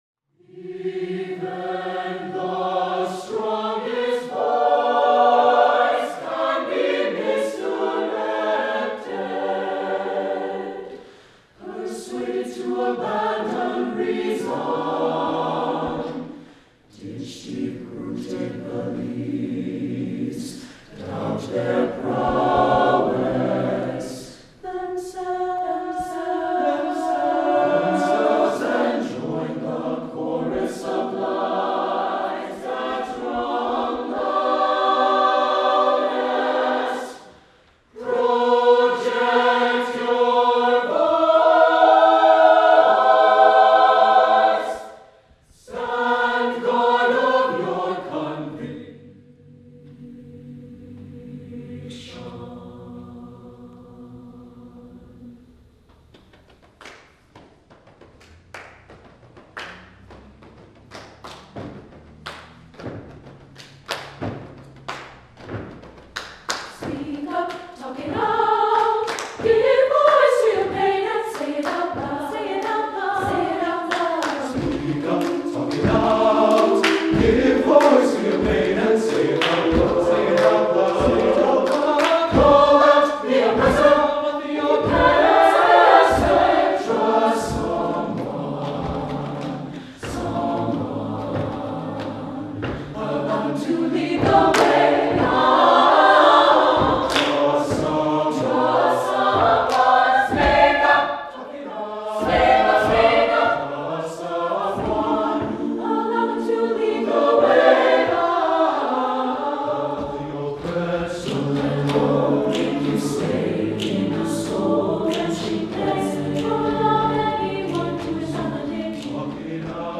SATB divisi choir, body percussion